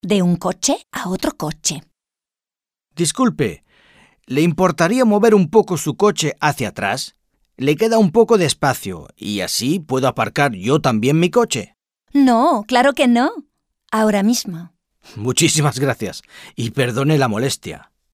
Dialogue - De un coche a otro coche